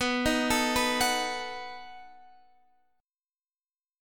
B7 Chord
Listen to B7 strummed